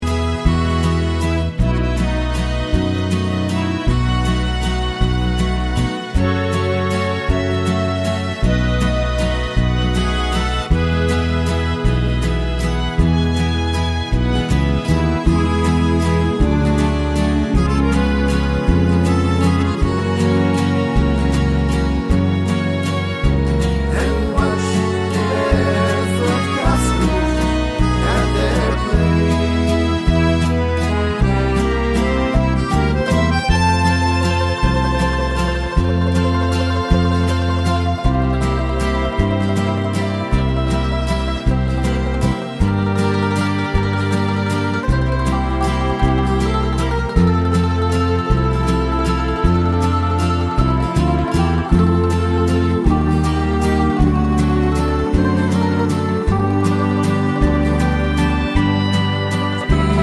no Backing Vocals Irish 3:36 Buy £1.50